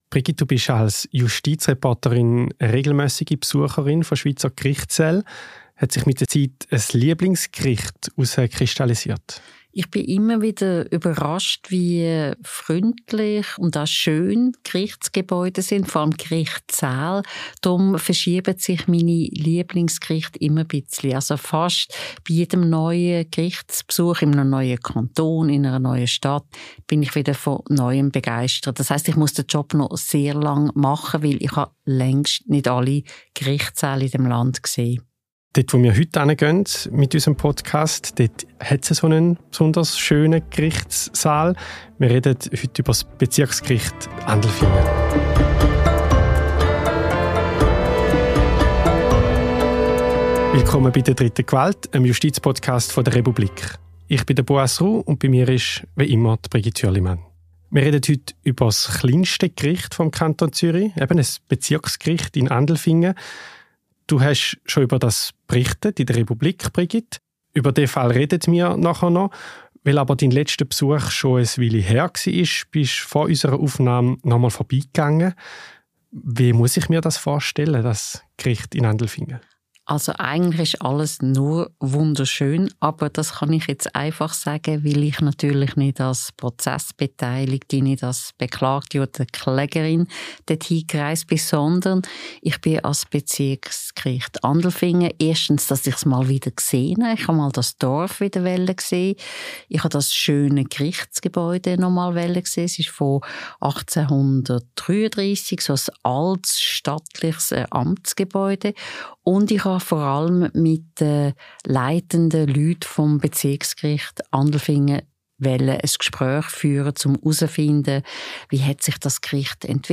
Wir statten dem kleinsten Bezirksgericht der Schweiz einen Besuch ab. Was kann man dort über Rechtssprechung lernen?